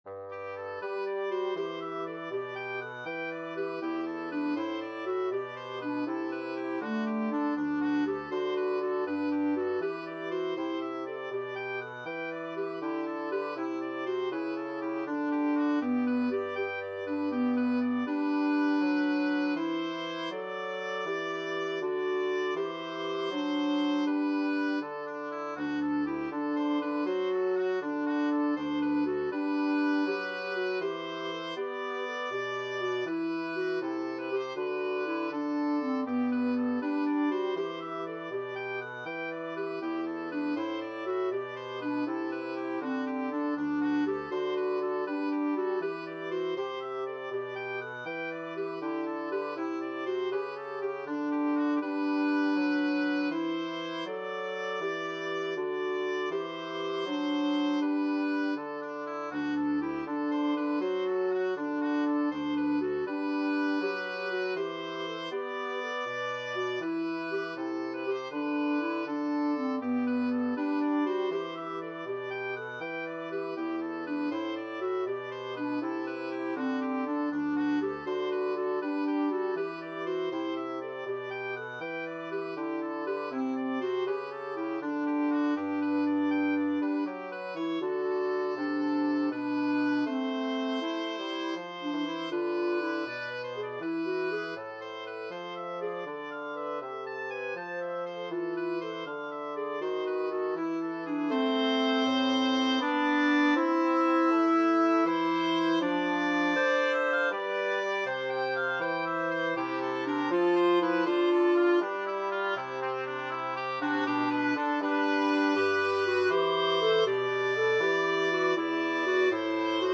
Oboe
Clarinet
Bassoon
3/4 (View more 3/4 Music)
Andante